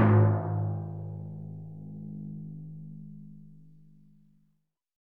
timpani and gong are non-harmonic.